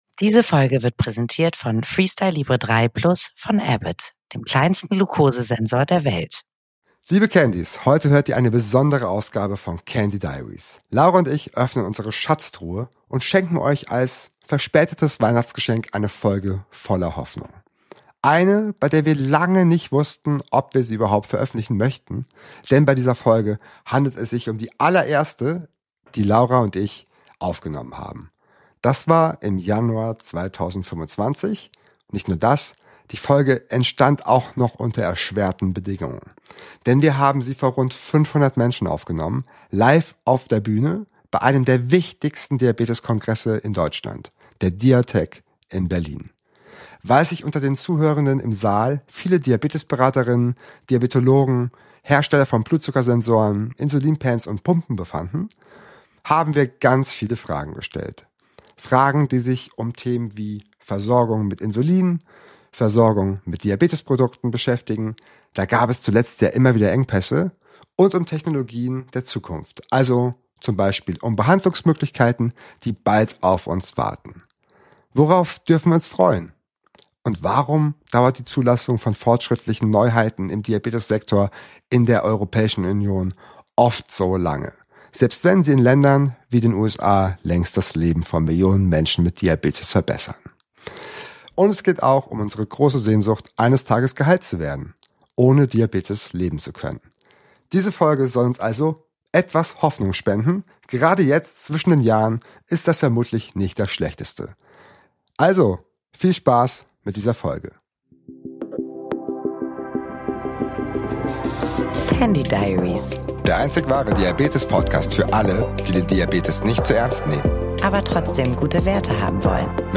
Candy Diaries: Die Hoffnungs-Folge – live von der Diatec